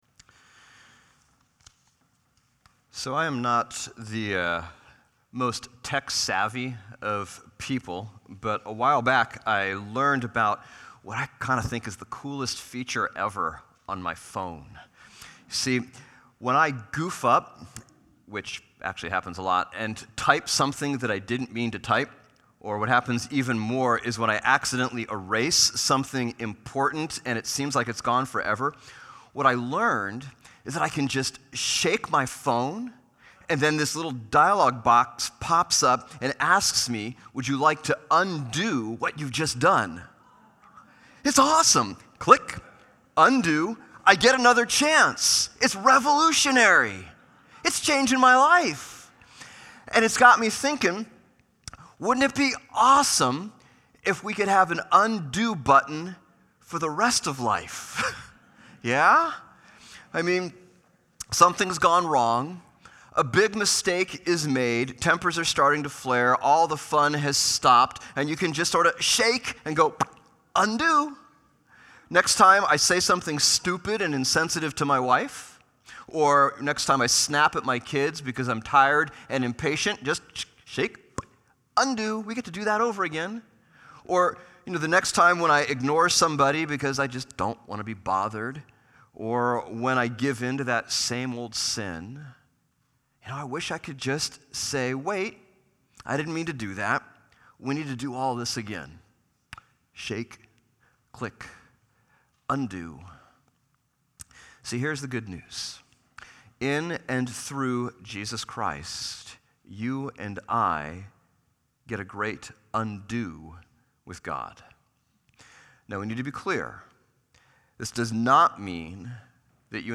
Jonah sermon series throughout Lent